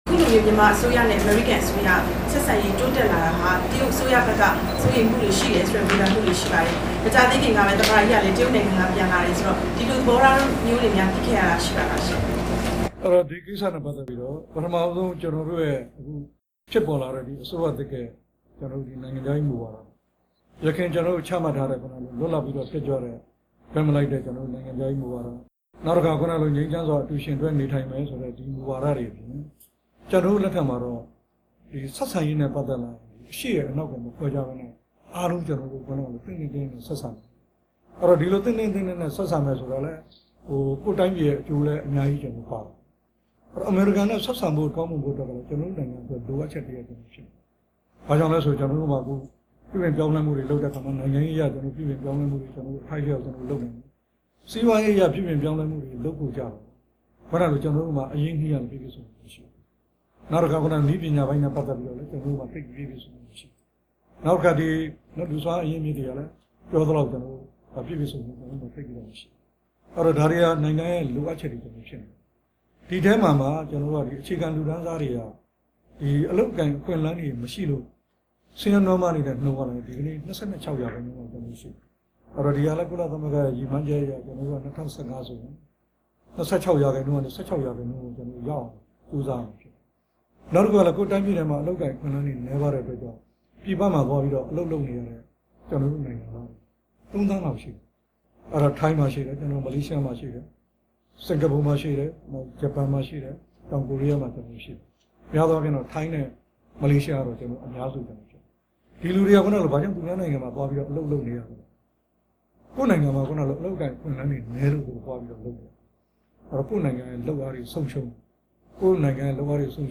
သမ္မတကြီး ဦးသိန်းစိန်နှင့် RFA အမေးအဖြေ ဒုတိယပိုင်း